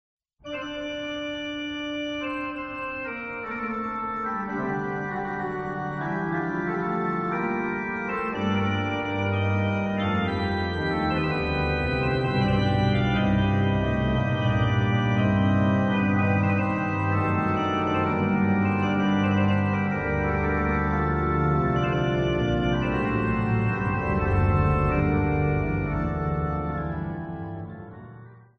His fugues have five voices: two voices for the right hand, two for the left hand in another timbre, and one for the pedal.
Grigny: Integrale de l’oeuvre d’orgue. By: André Isoir (Calliope 3911-2)
degrignyfuga.mp3